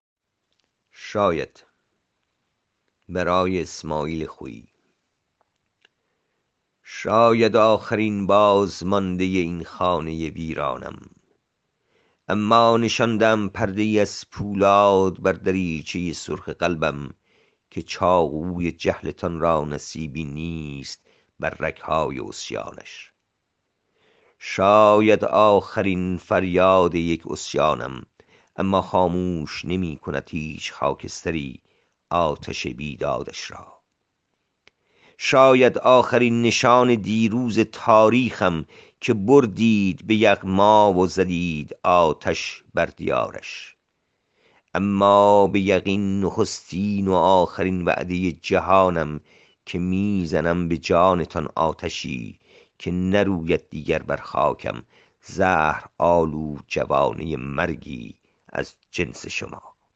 این شعر را با صدای شاعر بشنوید